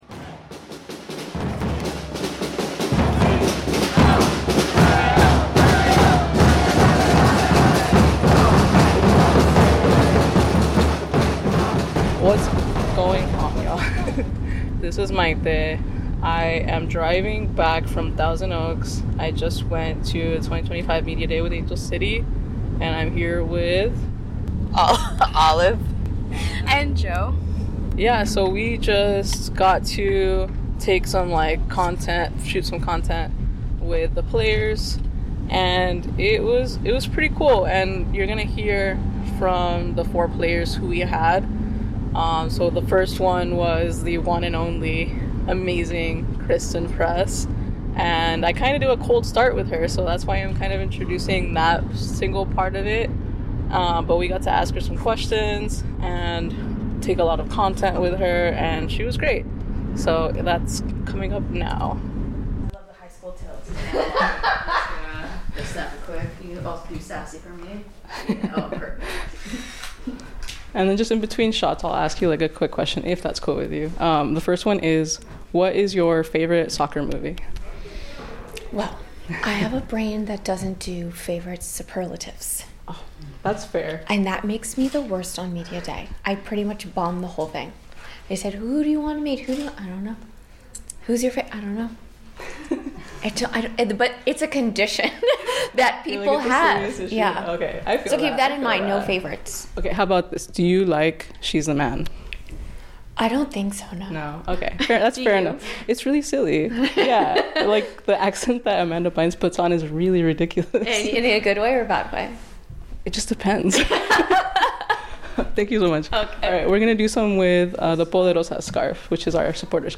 Listen to the raw audio from our Media Day with ACFC!
We interview all 4 of these amazing players and learn about them in between photos.